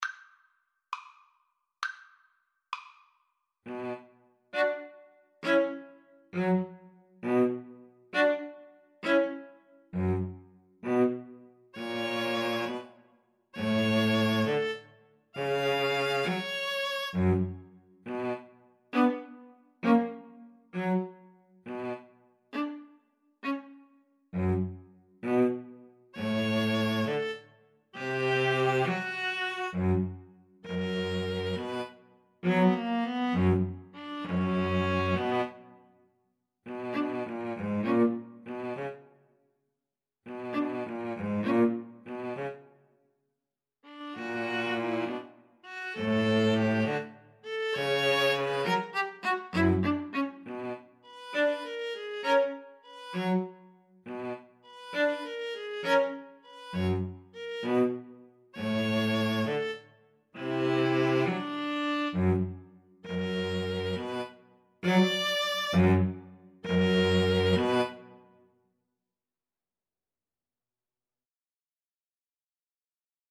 Moderato
6/8 (View more 6/8 Music)
Classical (View more Classical String trio Music)